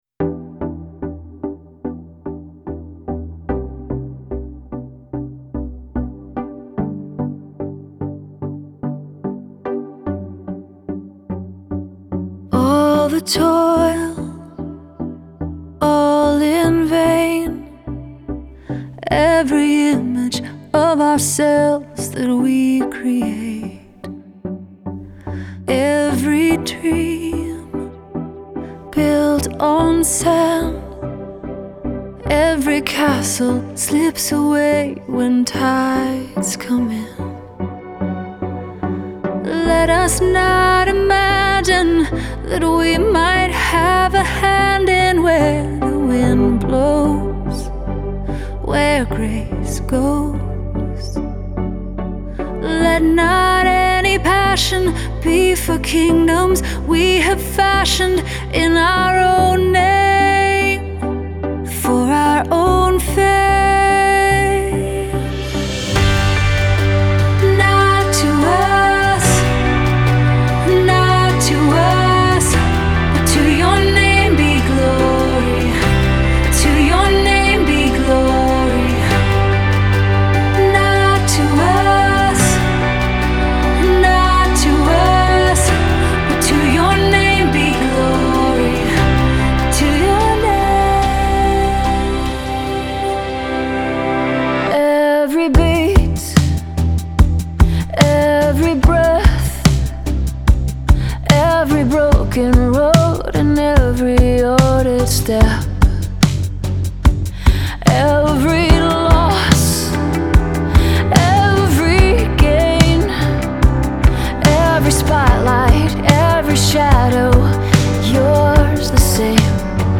Genre: CCM, Pop, Pop Rock